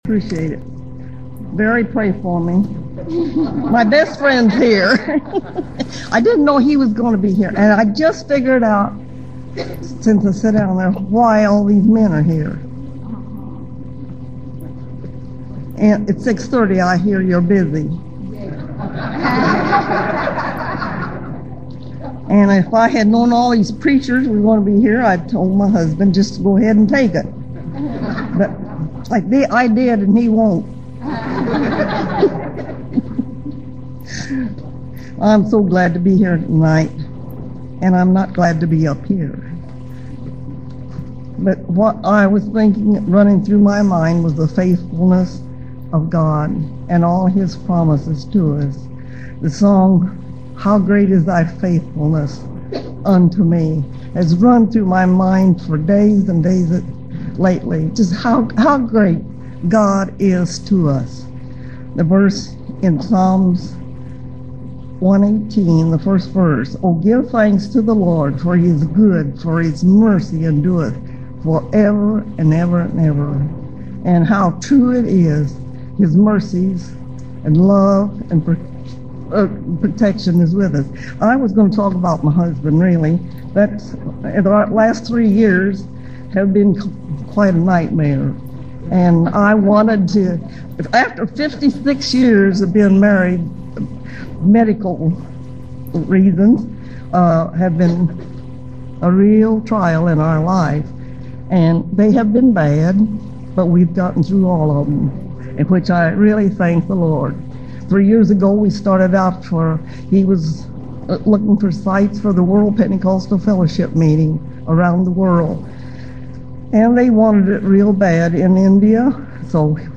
Testimony
Women Preachers